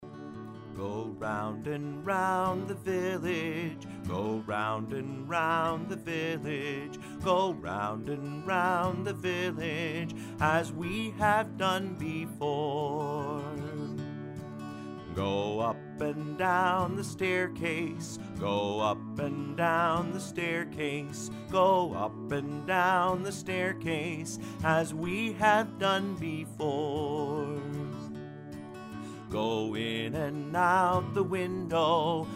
Children's Song Lyrics and Sound Clip